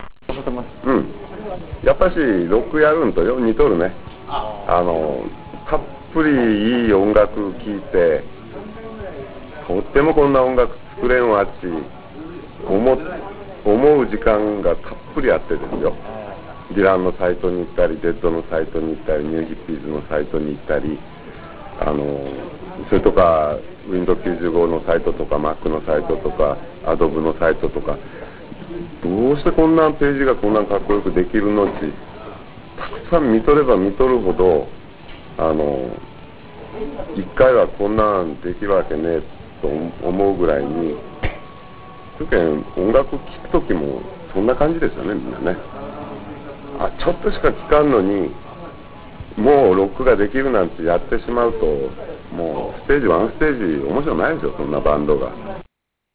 鮎川氏のナマの声を聴けるチャンスです。